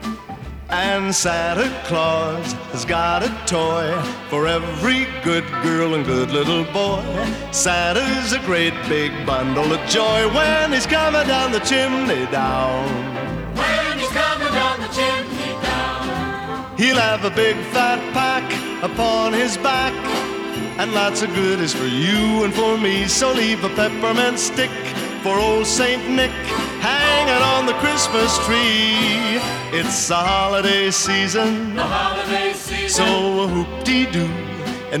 Vocal Pop
Жанр: Поп музыка